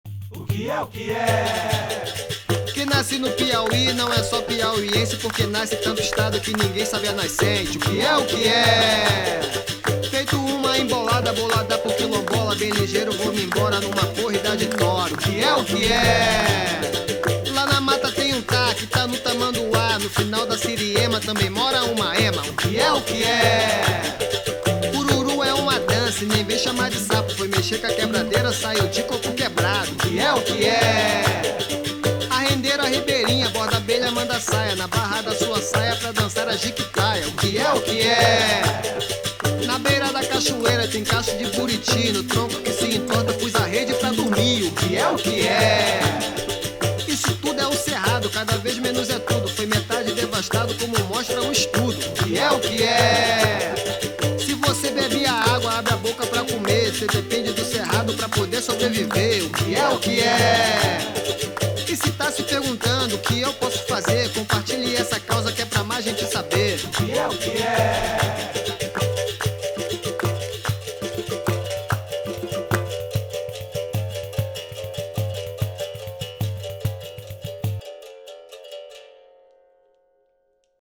Embolada-O-que-e-o-que-e-o-Cerrado.mp3